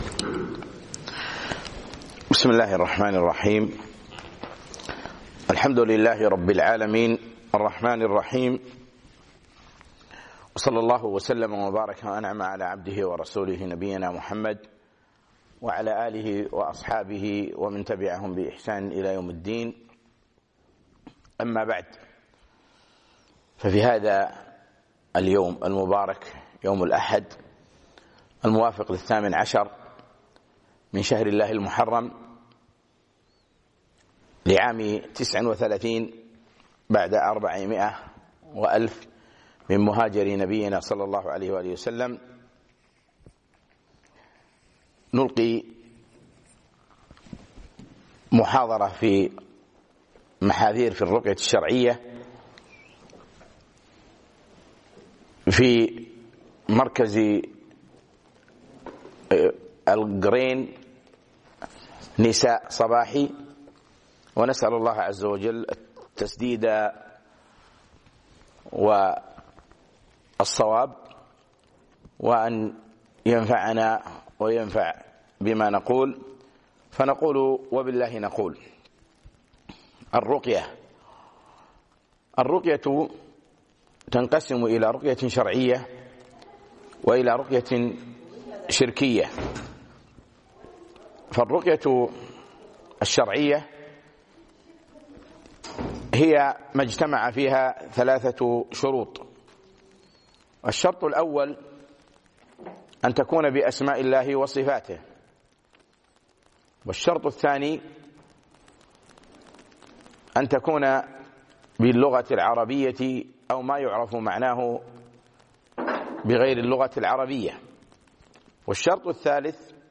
محاذير في الرقية الشرعية - محاضرة بدولة الكويت